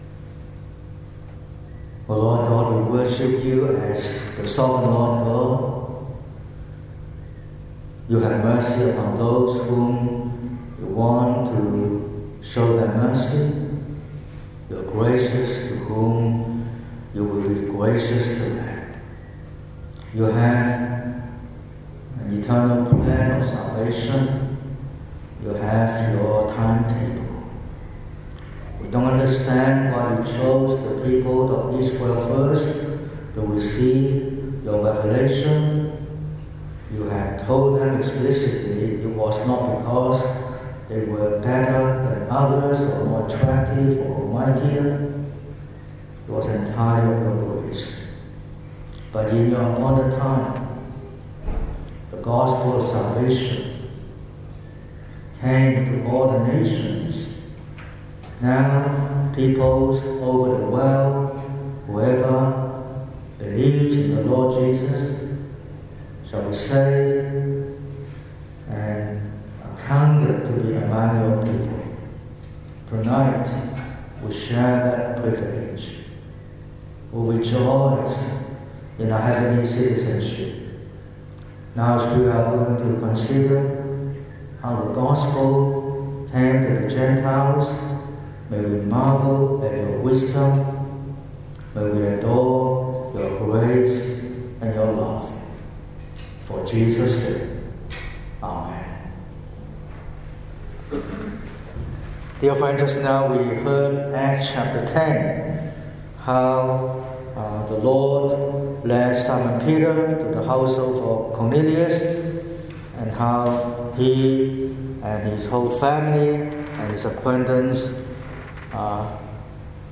19/04/2026 – Evening Service: The Gentiles’ Pentecost